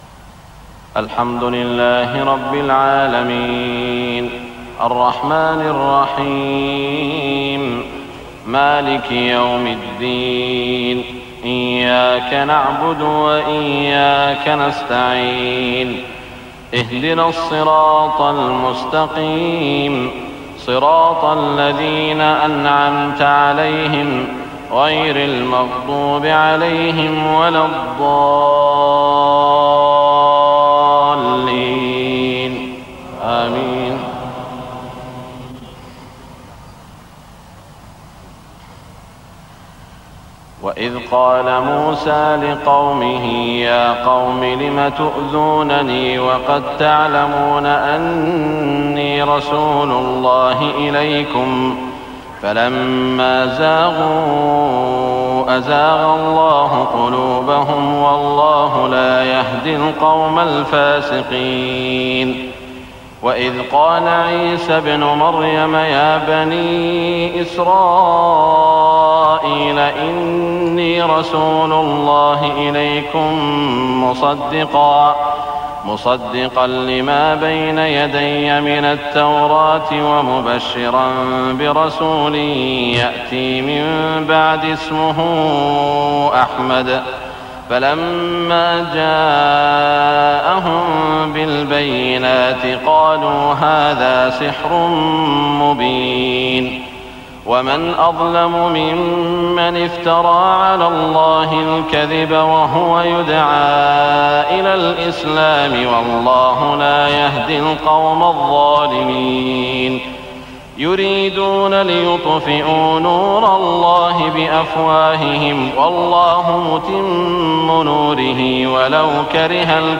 صلاة العشاء 1420 سورة الصف > 1420 🕋 > الفروض - تلاوات الحرمين